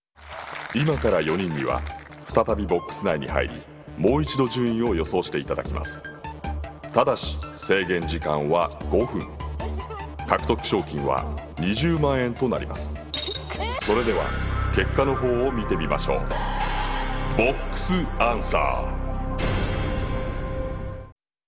沁みわたる深く優しい低音と、大きな身体から溢れ出る声量が武器。